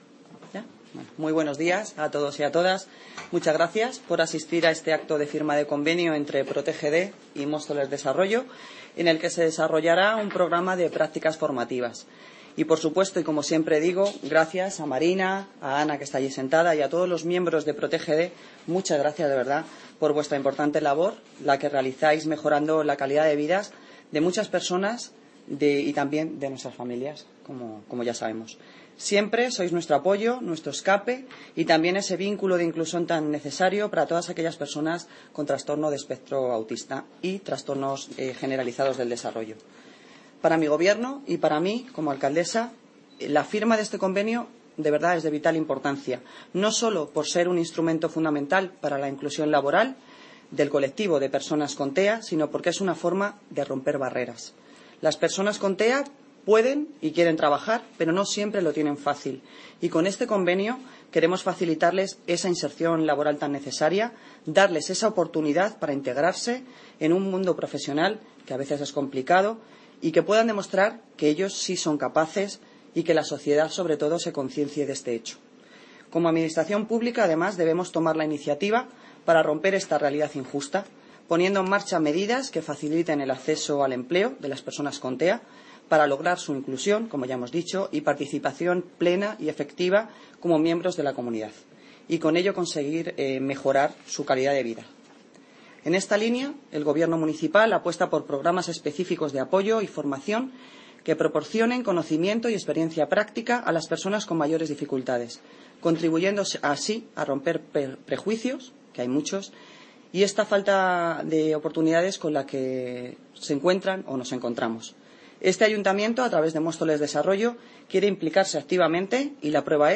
Audio - Noelia Posse (Alcaldesa de Móstoles) Sobre Convenio PROTGD